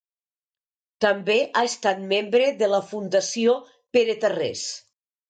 Pronounced as (IPA) [ˈpe.ɾə]